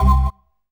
ORGAN-25.wav